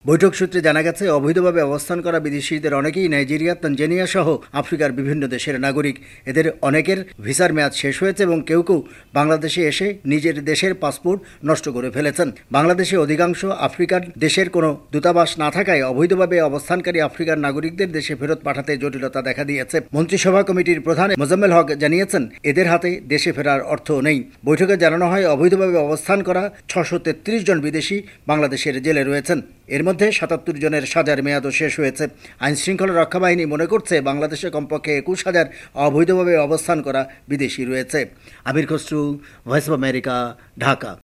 ঢাকা থেকে
রিপোর্ট